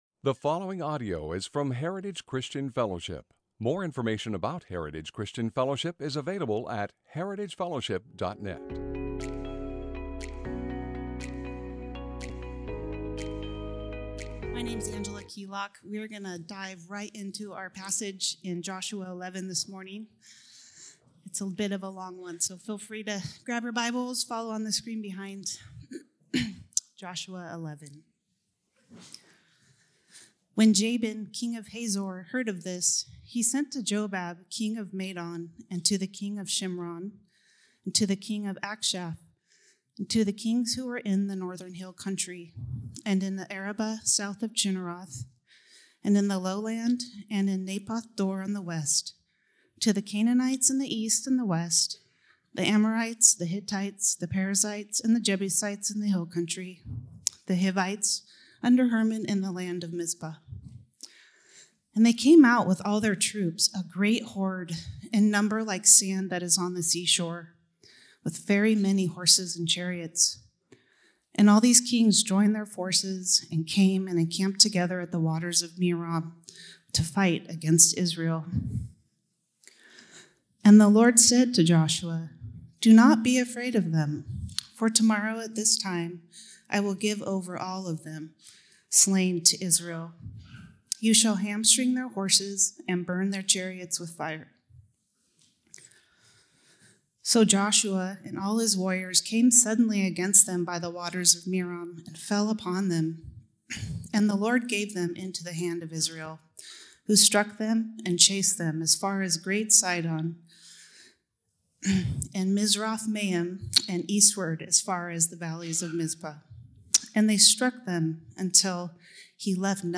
Sermons - Heritage Christian Fellowship | Of Medford, OR